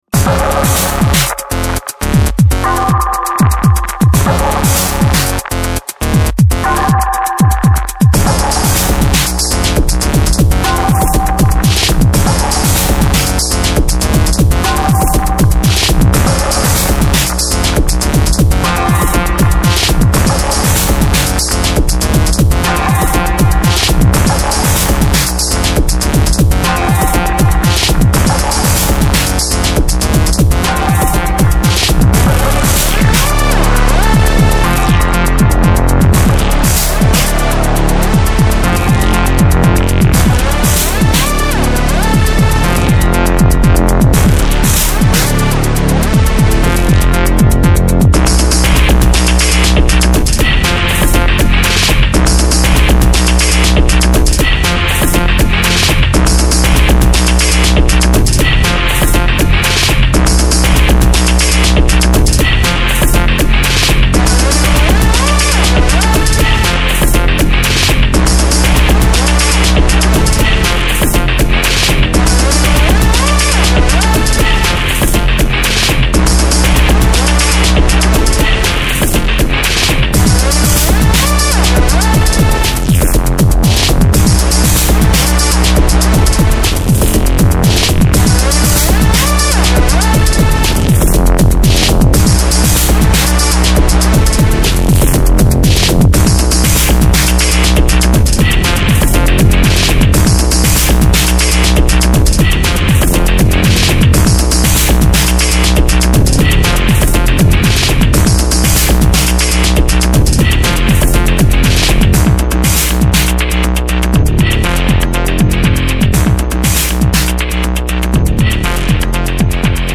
Dark electronica
Leftfield/noise
Ambient
Electro